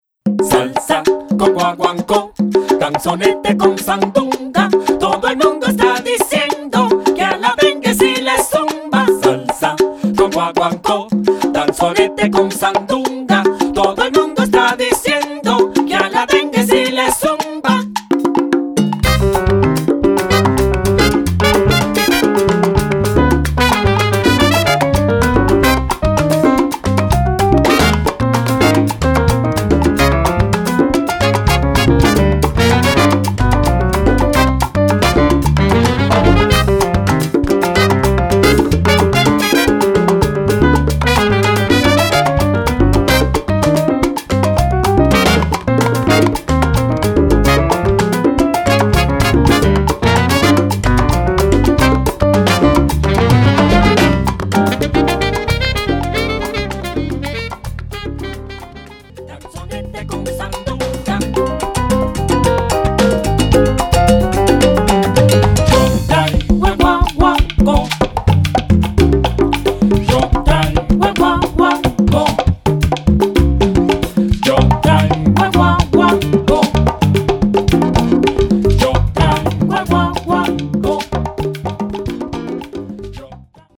Category: salsa (octet)
Style: mambo
Solos: open